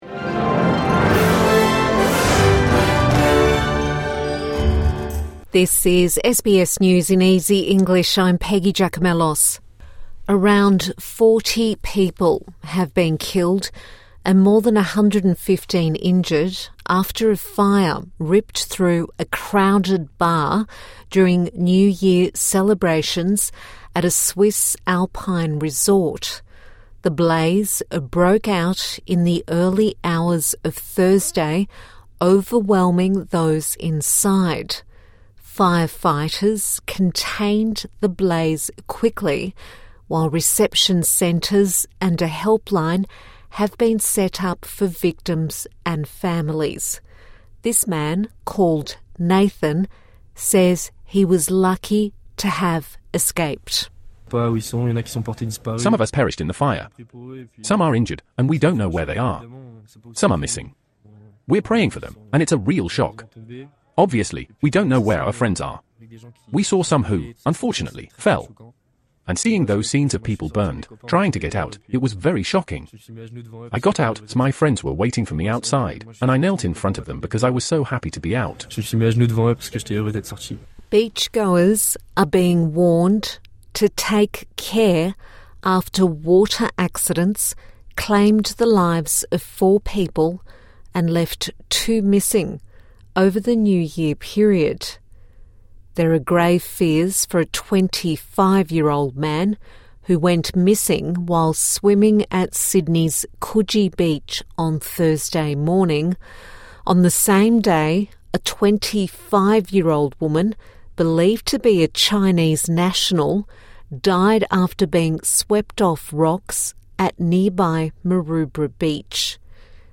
A five minute bulletin for English language learners